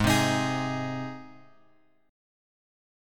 G# chord {4 3 x x 4 4} chord
Gsharp-Major-Gsharp-4,3,x,x,4,4.m4a